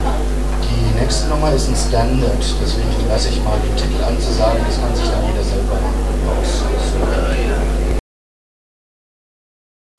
Ansage